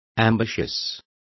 Complete with pronunciation of the translation of ambitious.